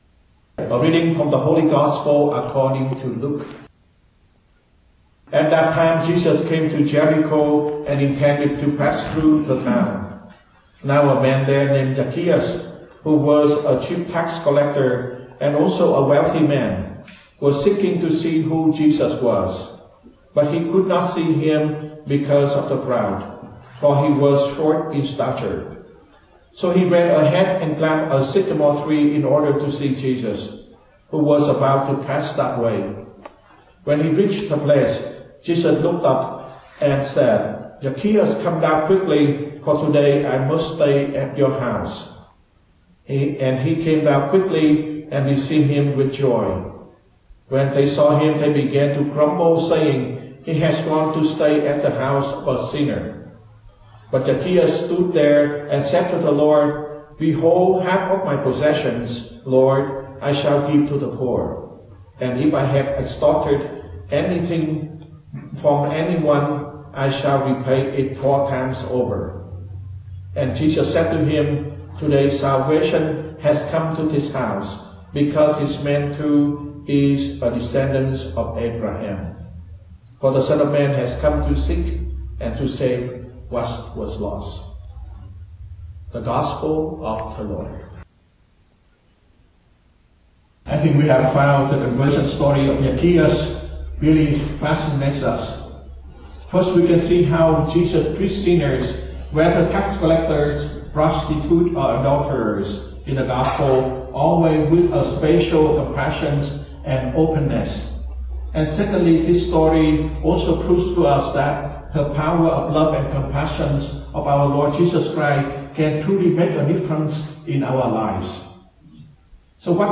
Homilies – November 2019